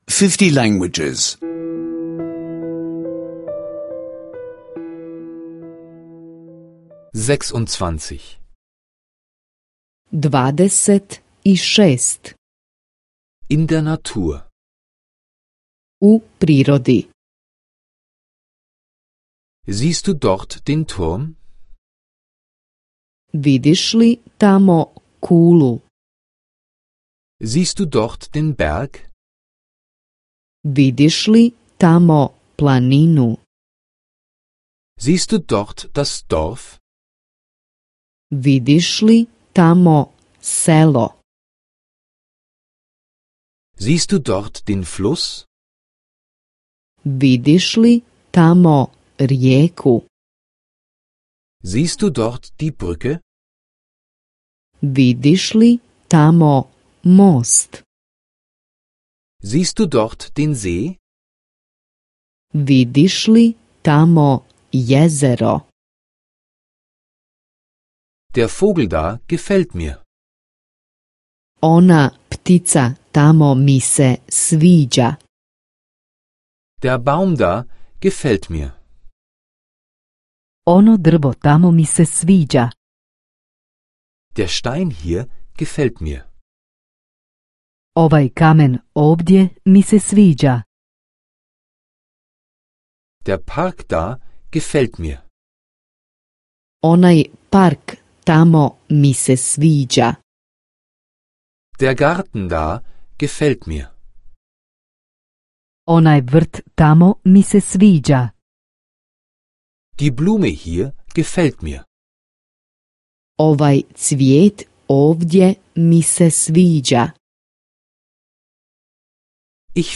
Bosnisch Sprache-Audiokurs (kostenloser Download)